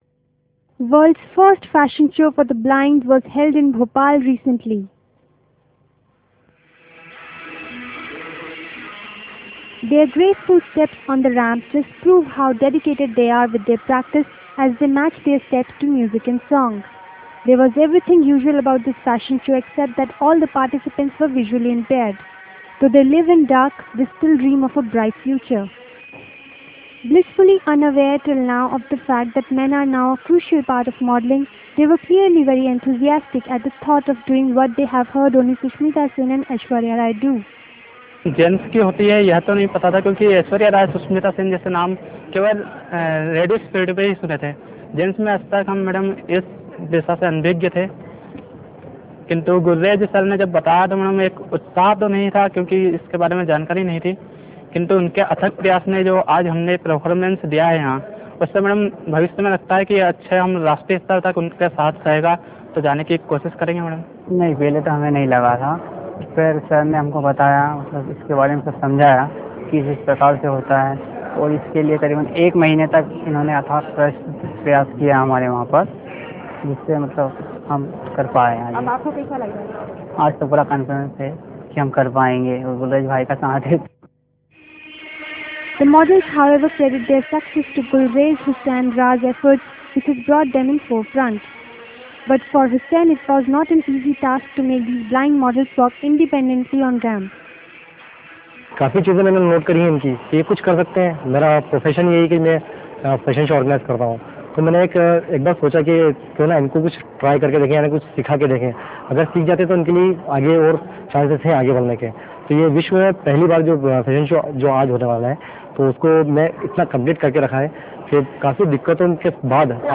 World's first fashion show for the blind was held in Bhopal recently.
Their graceful steps on the ramp prove how dedicated they are in their practice as they step to the beat.